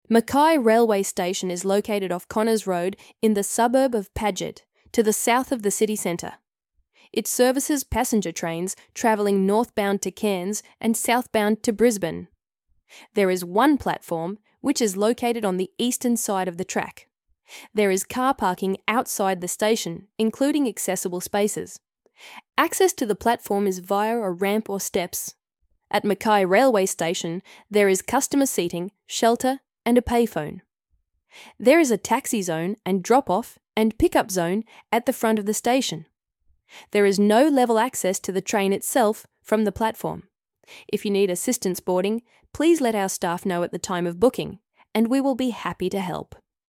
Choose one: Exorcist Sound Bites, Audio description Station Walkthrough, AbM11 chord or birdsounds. Audio description Station Walkthrough